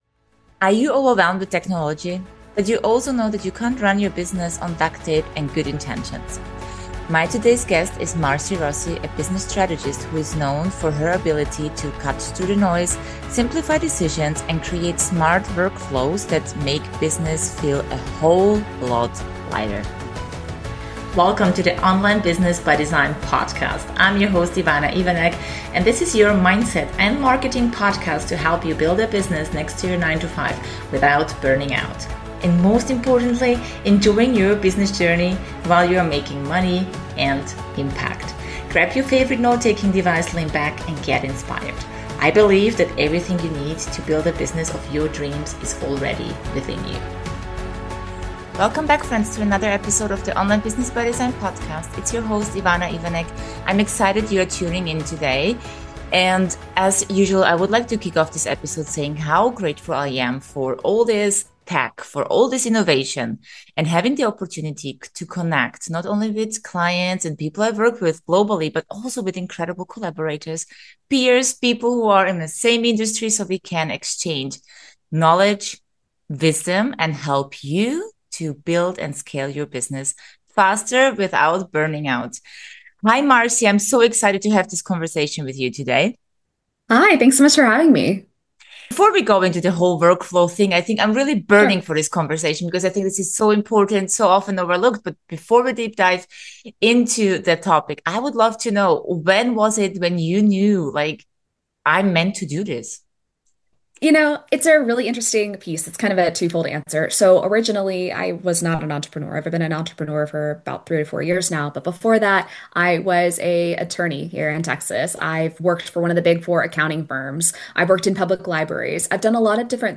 a business strategist renowned for transforming chaos into streamlined workflows. They discuss the importance of setting up automated systems for scaling your business, the psychological elements of customer experience, and the crucial role of keeping a personal touch in automated communication.